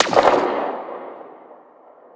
Zapper_far_03.wav